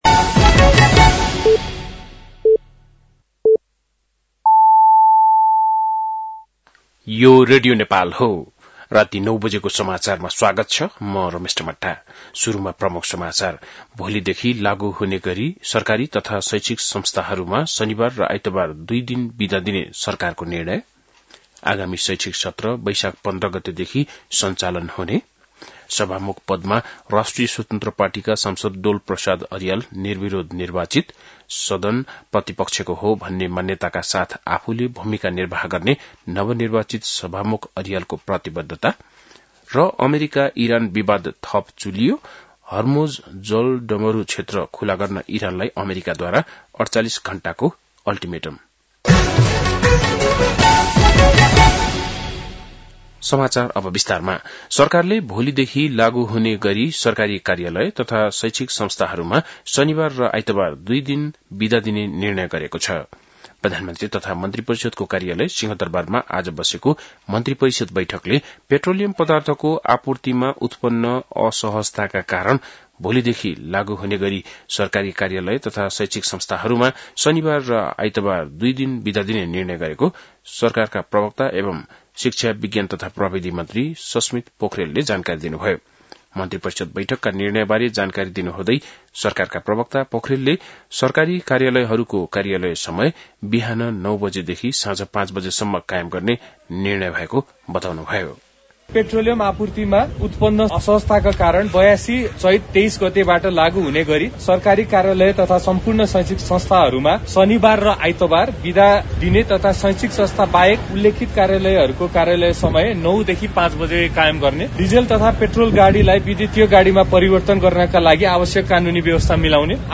बेलुकी ९ बजेको नेपाली समाचार : २२ चैत , २०८२
9-pm-nepali-news-12-22.mp3